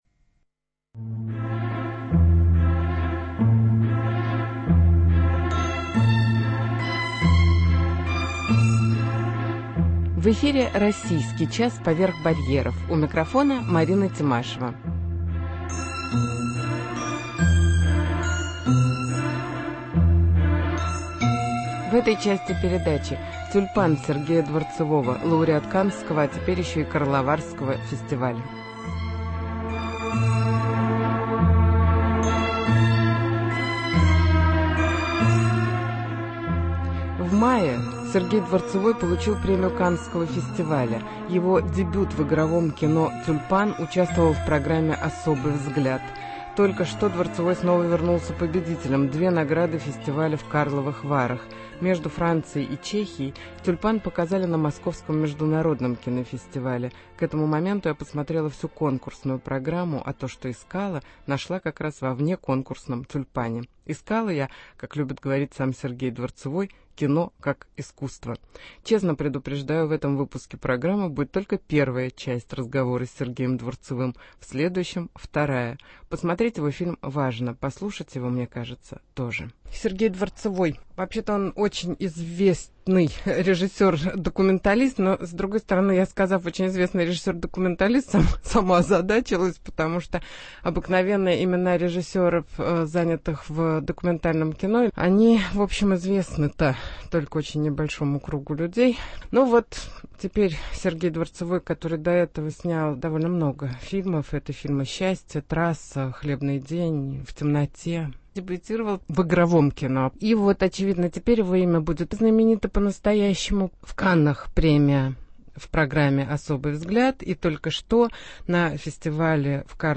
Интервью с Сергеем Дворцевым - лауреатом Каннского и Карловарского фестивалей (первая часть беседы - о кинодокументалистике)